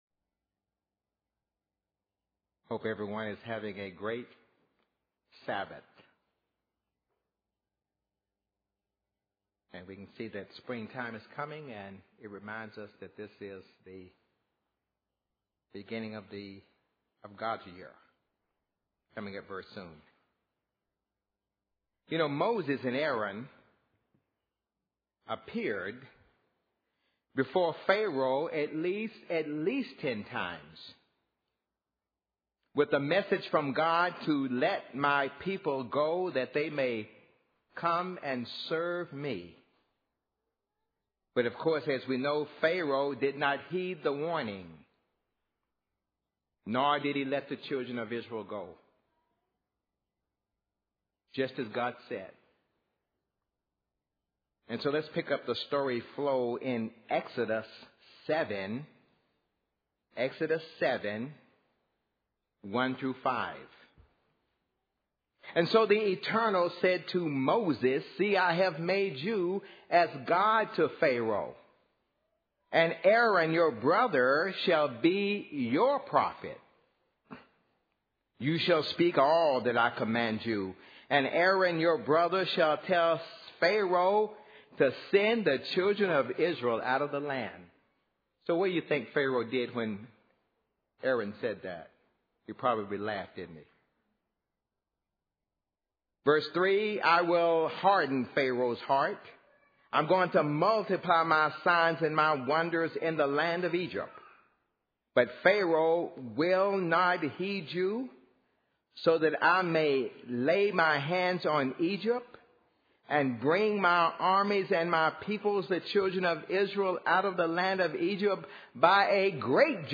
Given in Nashville, TN
Easter: The Rest of the Story UCG Sermon Studying the bible?